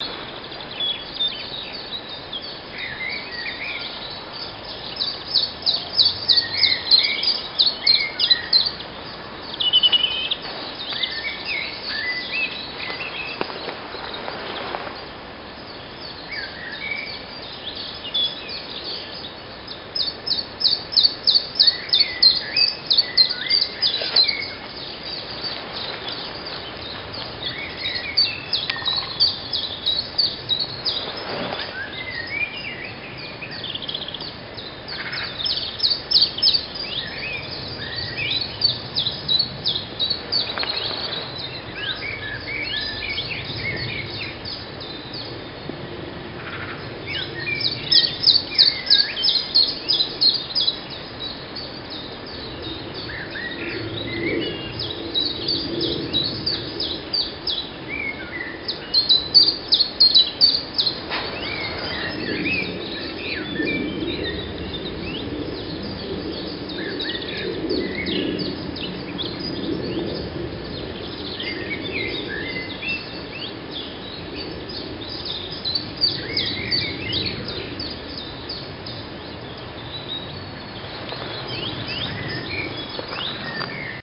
池塘边的鸟儿
描述：从森林（水，鸟，昆虫）环绕的池塘流出水边的声音
标签： 森林 昆虫 六月 捷克 自然 现场录音
声道立体声